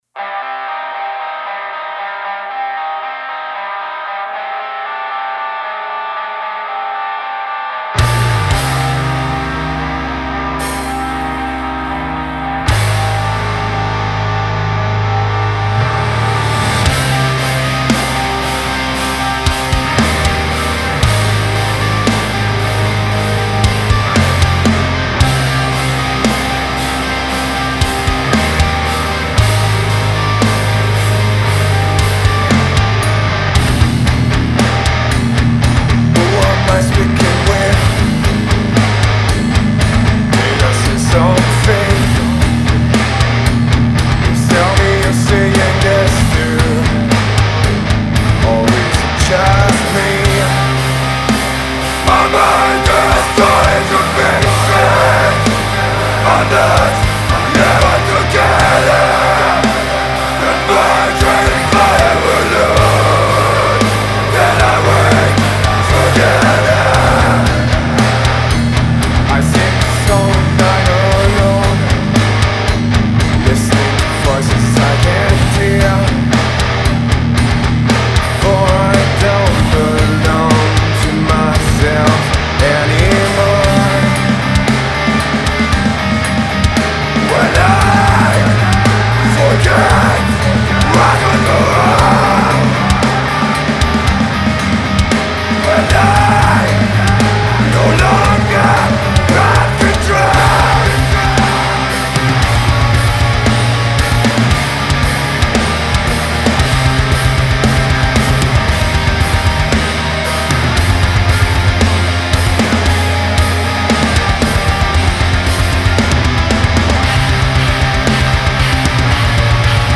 Style : Death-Metal Progressif